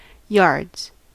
Ääntäminen
Ääntäminen US Tuntematon aksentti: IPA : /ˈjɑɹdz/ IPA : /ˈjɑːdz/ Haettu sana löytyi näillä lähdekielillä: englanti Yards on sanan yard monikko.